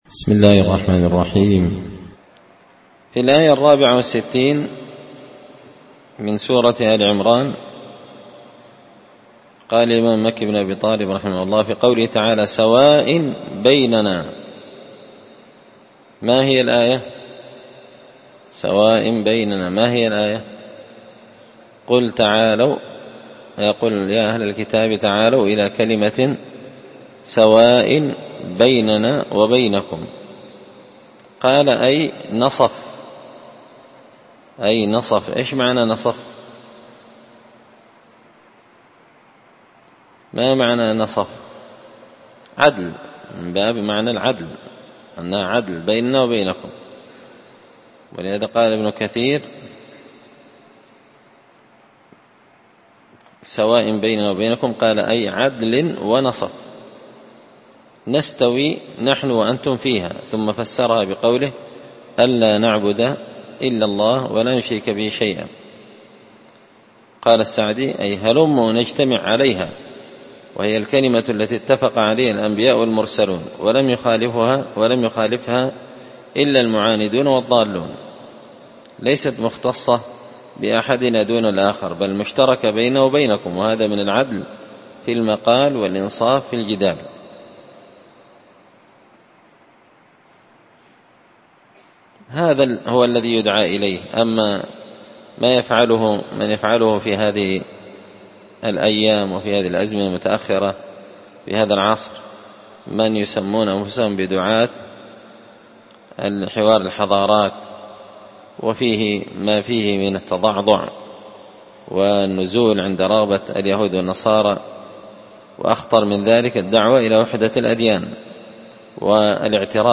تفسير مشكل غريب القرآن ـ الدرس 65
دار الحديث بمسجد الفرقان ـ قشن ـ المهرة ـ اليمن